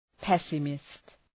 Προφορά
{‘pesəmıst}